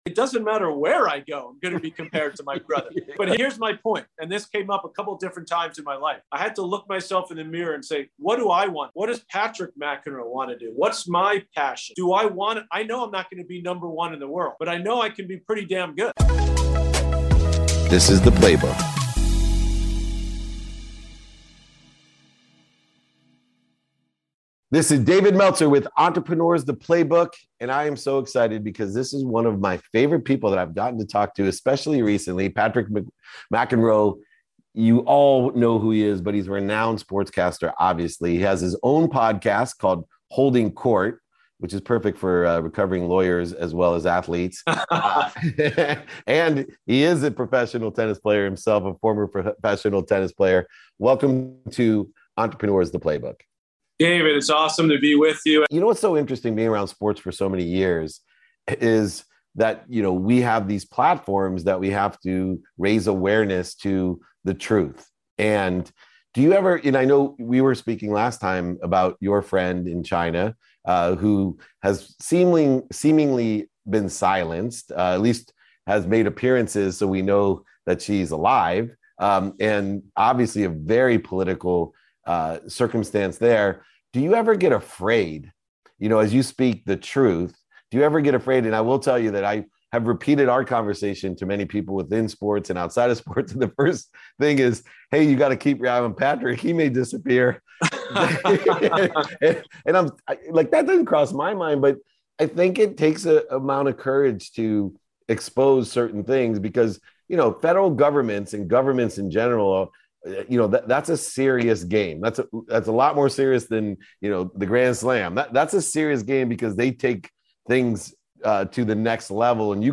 Living The Life That You Want | Interview With Patrick McEnroe, Renowned Sports Broadcaster, Host of the “Holding Court” Podcast
Patrick McEnroe, Renowned Sports Broadcaster, Host of the “Holding Court” Podcast, and Former Professional Tennis Player, sits down to share: